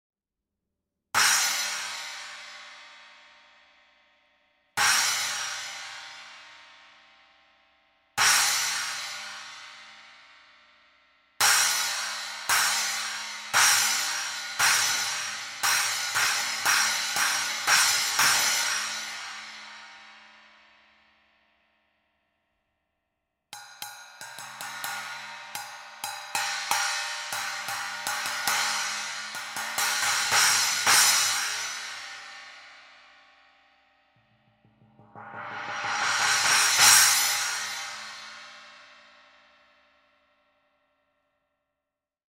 12″ Armor Trash Splash Cymbals (Approx 480 grams):
12__Armor-Trash_Splash.mp3